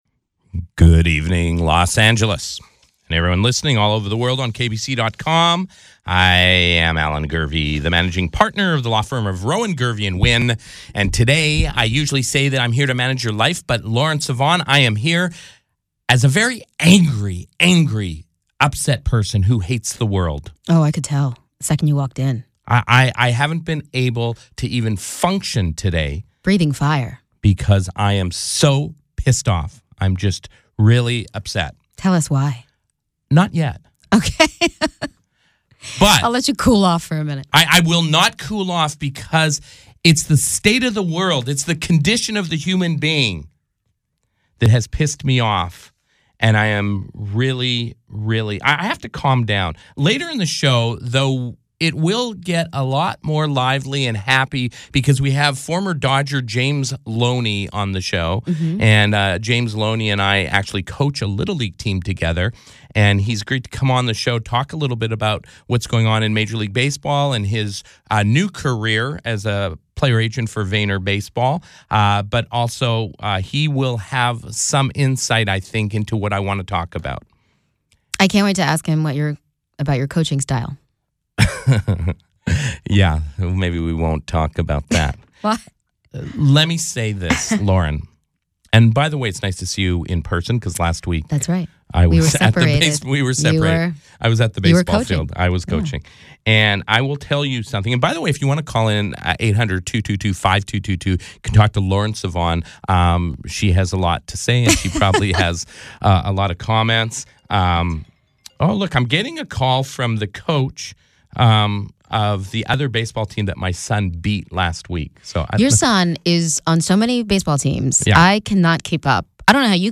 former Dodger first baseman James Loney
the latest Gurvey’s Law podcast, which shocked the airwaves on KABC-AM 790 TalkRadio